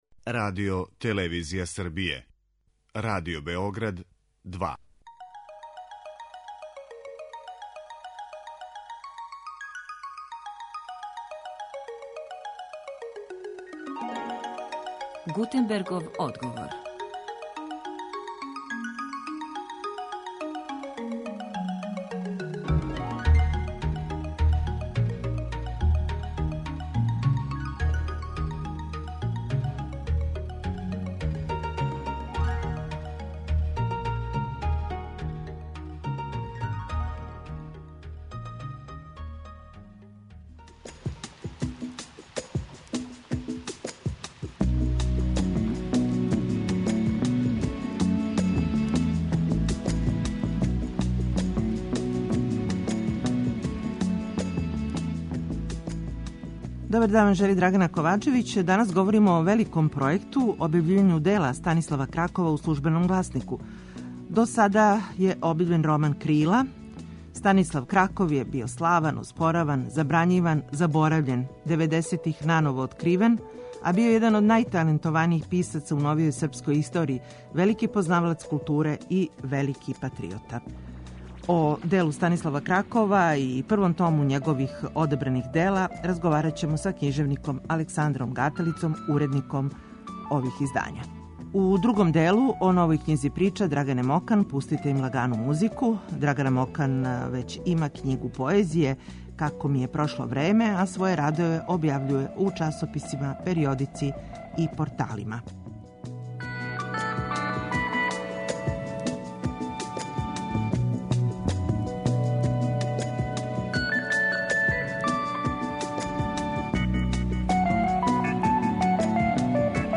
Гост емисије је књижевник Александар Гаталица, уредник издања.